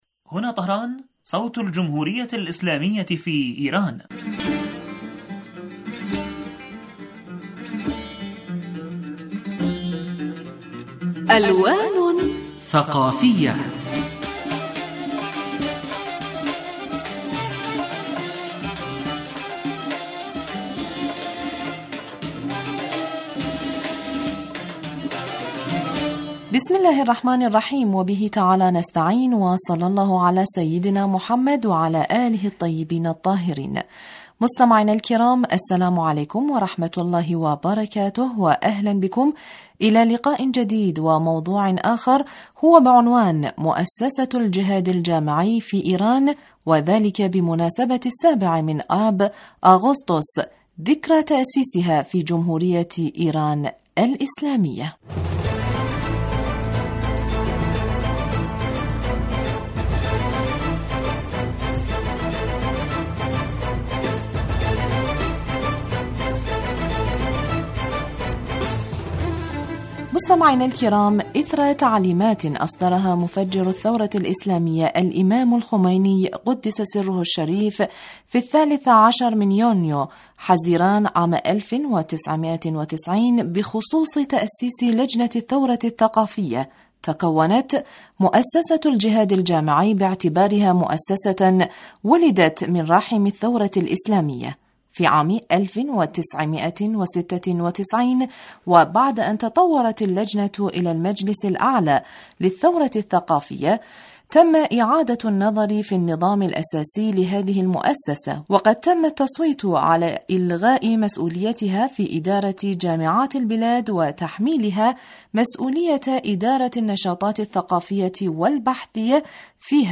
لقاء ثقافي وأدبي یجمعنا بکم أسبوعیا عبر تجوال ممتع في أروقة الحقول الثقافیة والحضاریة والأدبیة لإيران الإسلامية ویشارکنا فیه عدد من الخبراء وذووي الاختصاص في الشأن الثقافي الإيراني لإيضاح معالم الفن والأدب والثقافة والحضارة في إیران